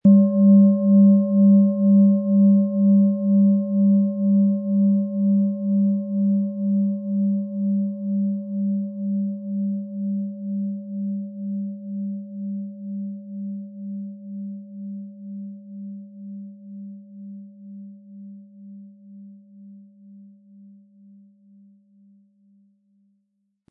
Tibetische Bauch-Klangschale, Ø 16 cm, 500-600 Gramm, mit Klöppel
Im Sound-Player - Jetzt reinhören hören Sie den Original-Ton dieser Schale.
HerstellungIn Handarbeit getrieben
MaterialBronze